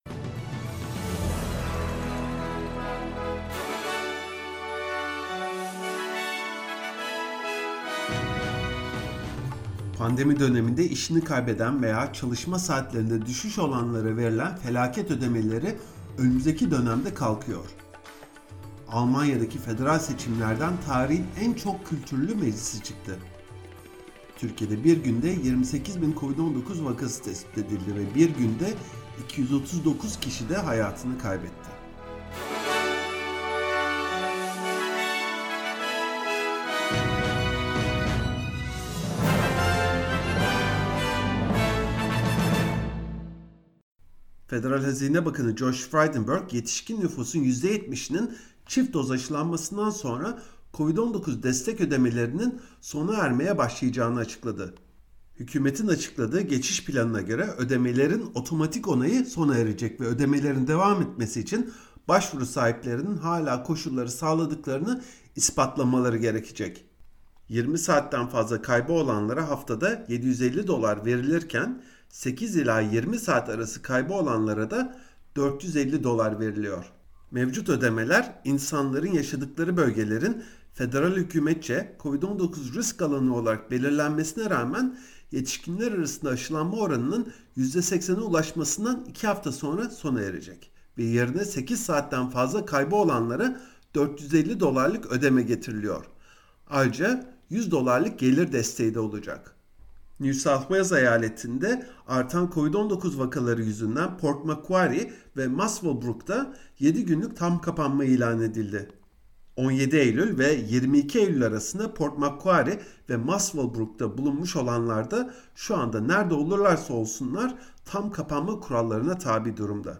SBS Türkçe Haber Bülteni Source: SBS